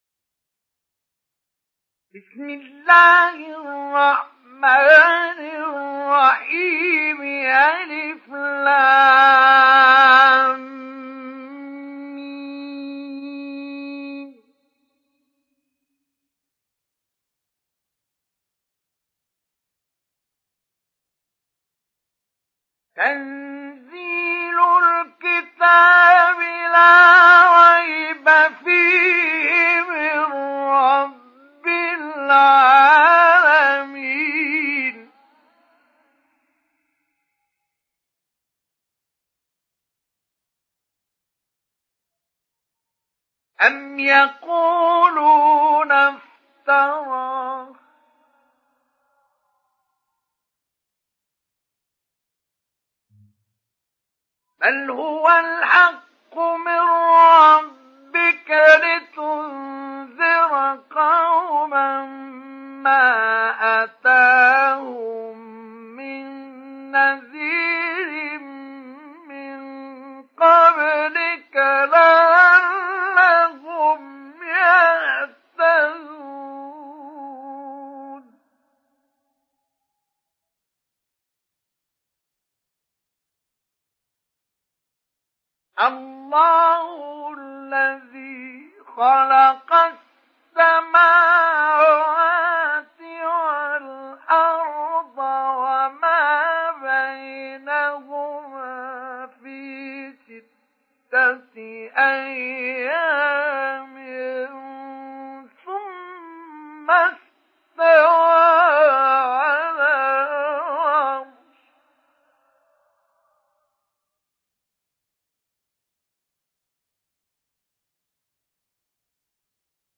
Surat As Sajdah Download mp3 Mustafa Ismail Mujawwad Riwayat Hafs dari Asim, Download Quran dan mendengarkan mp3 tautan langsung penuh
Download Surat As Sajdah Mustafa Ismail Mujawwad